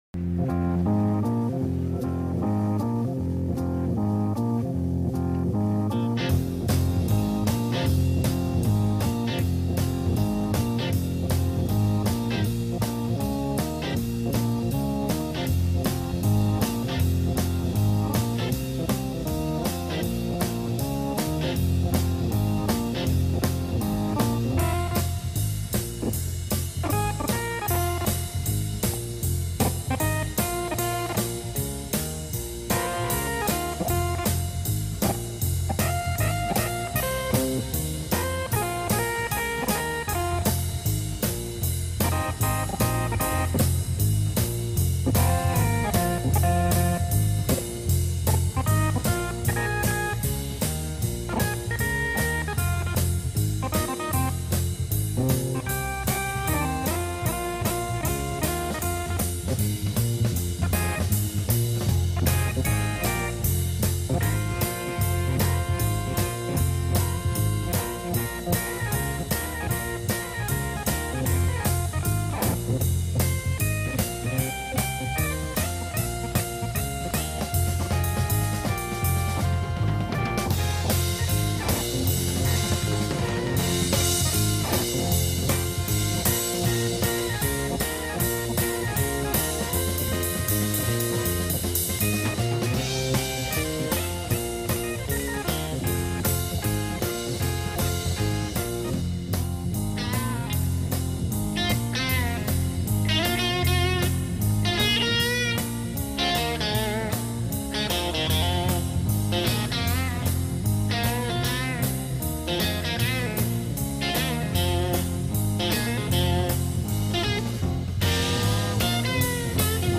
agrupación instrumental de música soul, rock y R&B
la pieza de rock instrumental